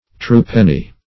True-penny \True"-pen`ny\